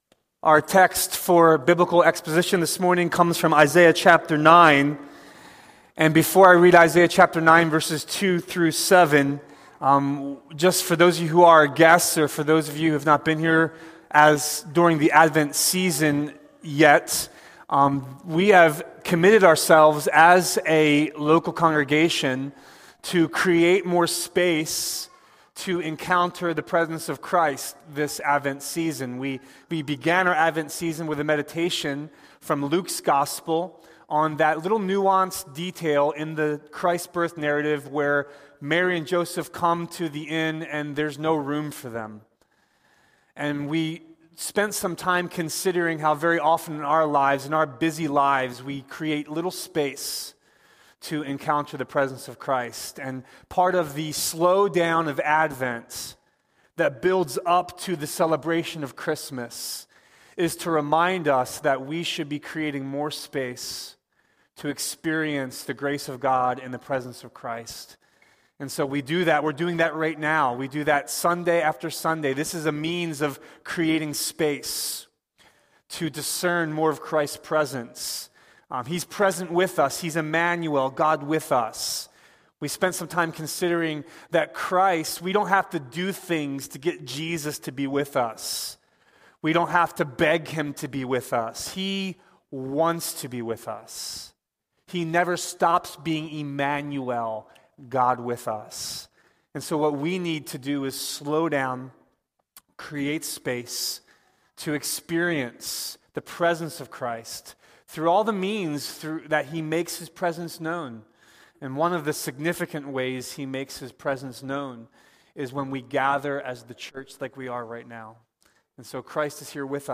Sermon
A sermon from the series "Stand Alone Sermons."